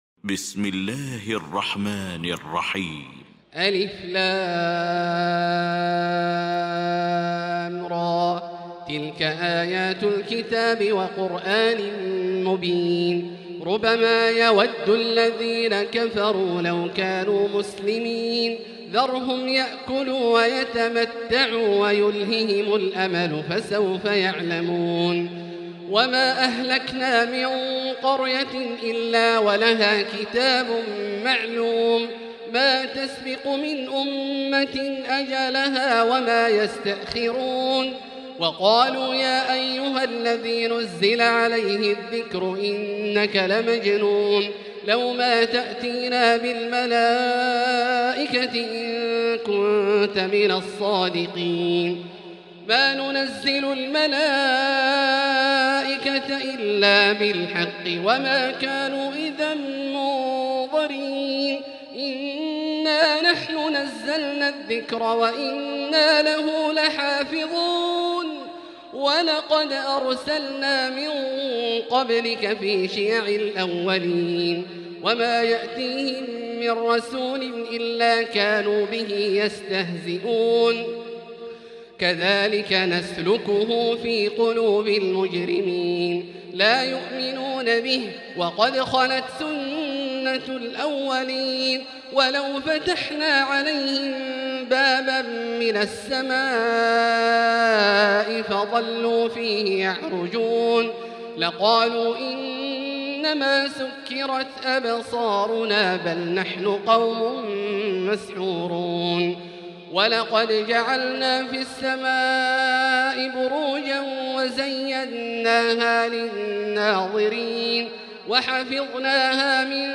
المكان: المسجد الحرام الشيخ: فضيلة الشيخ عبدالله الجهني فضيلة الشيخ عبدالله الجهني الحجر